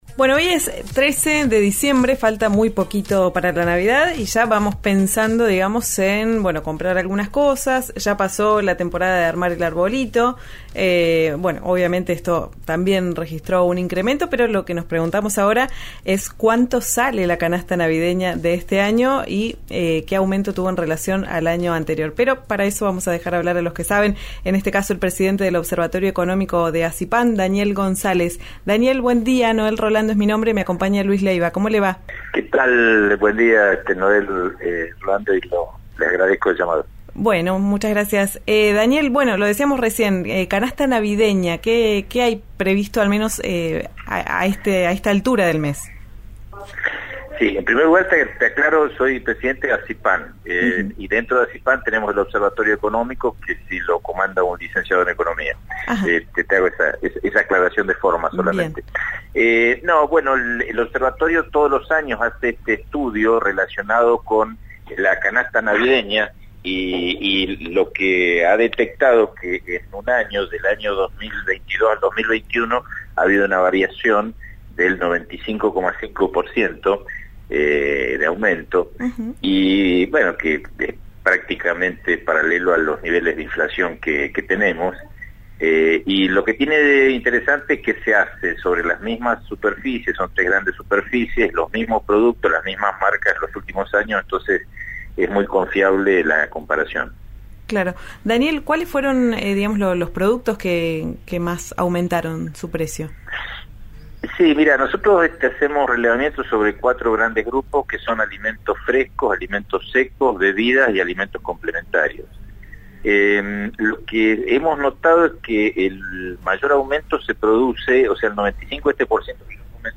en diálogo con «Ya es Tiempo» por RÍO NEGRO RADIO.